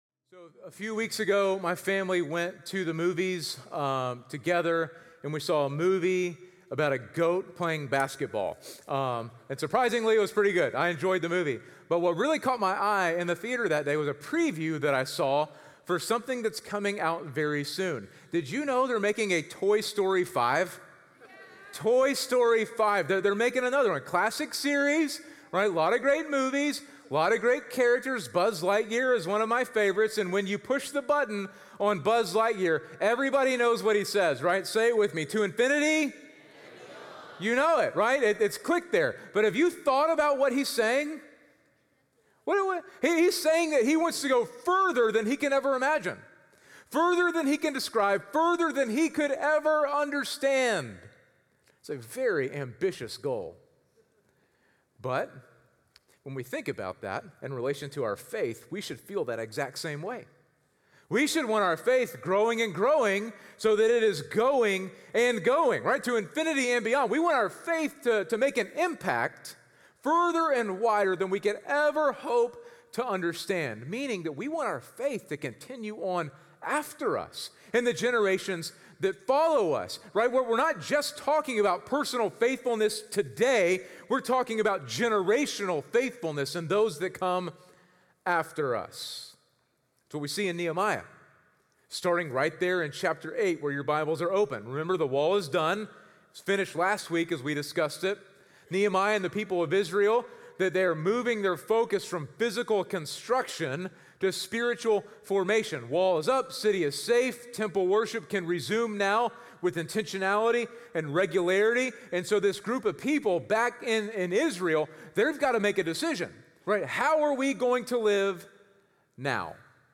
Sunday Sermons – Media Player